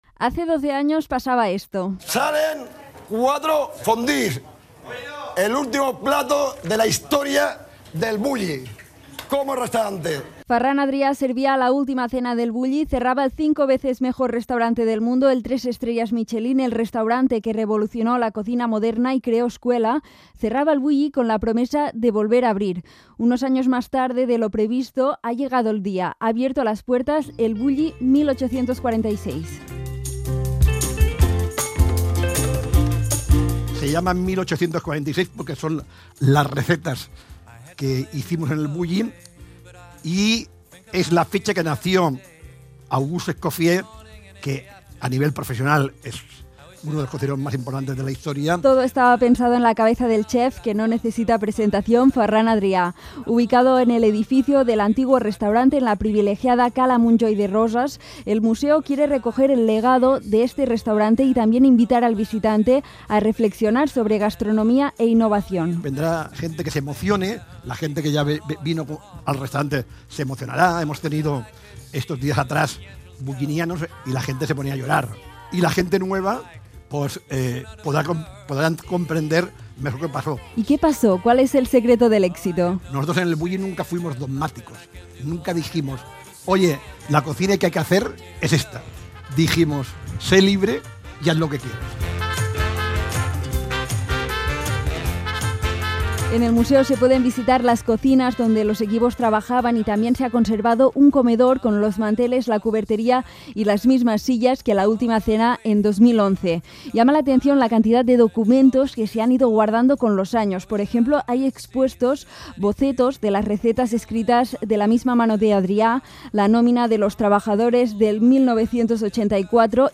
Reportaje | El Bulli se transforma en museo